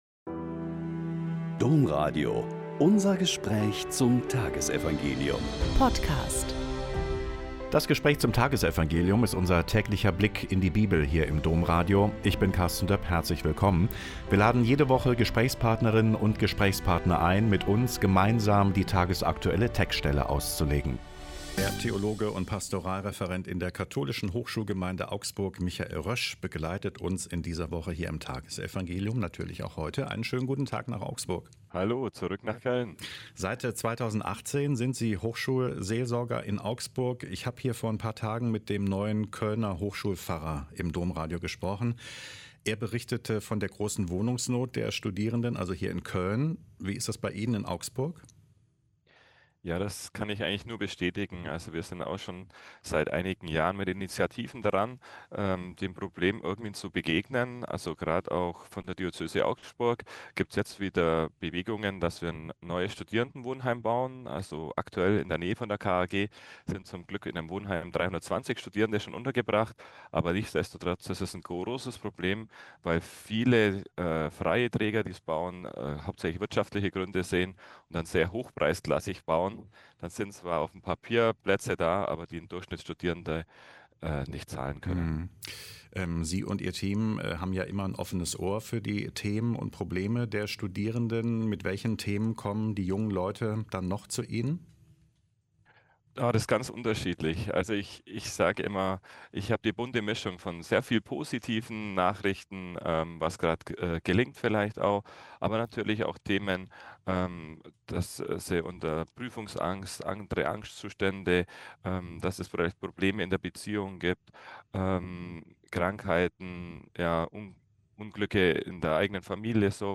Lk 11,37-41 - Gespräch